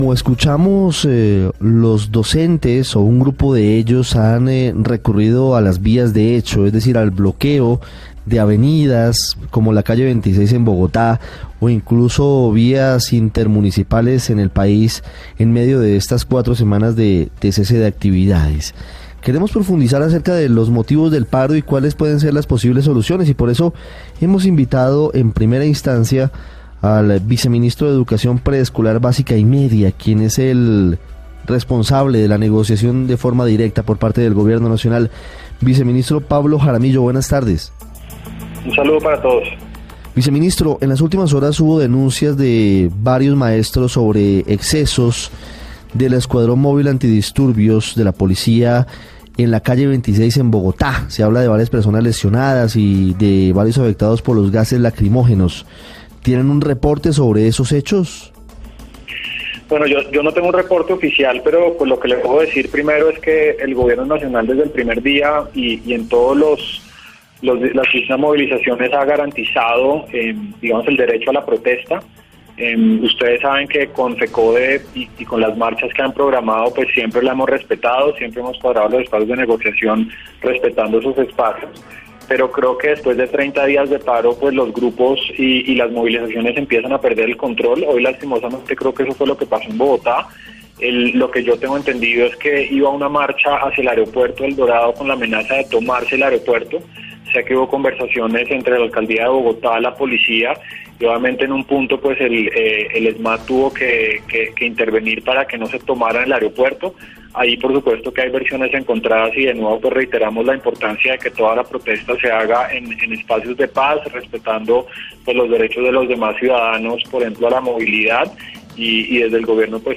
En El Radar hablan el viceministro de Educación